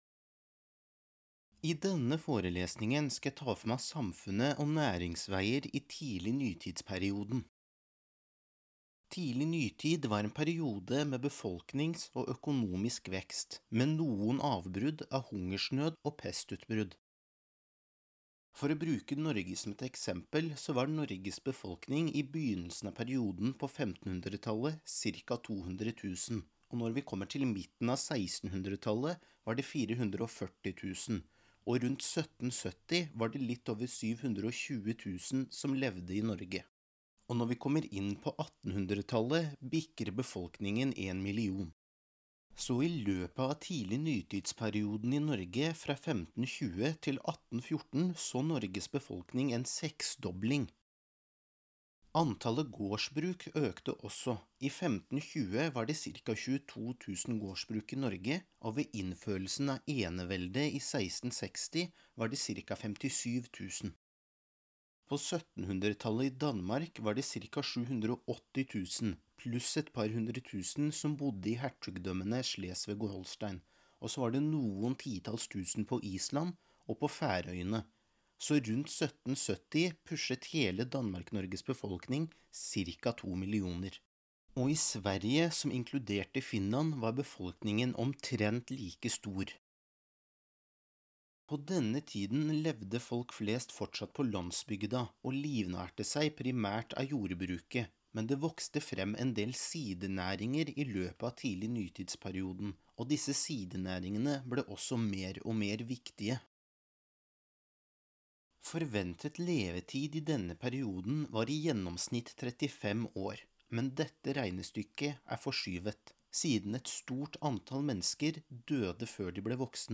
Podkast: Forelesning om utviklingen i befolkningen, næringsliv og religiøse lover i Norden under Tidlig Nytid (ca. 21 minutter)
Historiker │ Mastergrad, Universitetet i Oslo